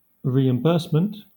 Ääntäminen
Southern England
IPA : /ˌɹiː.ɪmˈbɜːs.mənt/